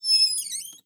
ClosetOpeningSound.wav